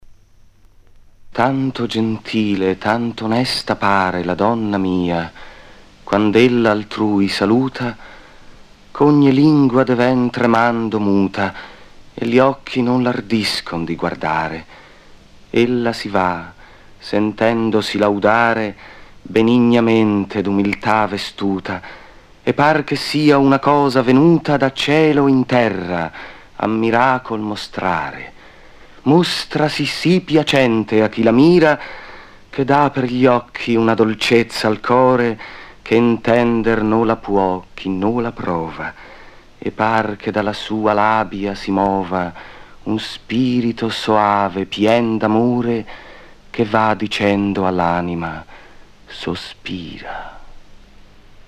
Giorgio Albertazzi recita: